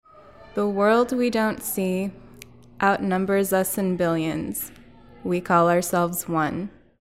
Audio Tour - Power of Poetry